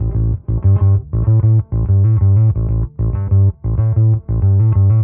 Index of /musicradar/dusty-funk-samples/Bass/95bpm
DF_PegBass_95-A.wav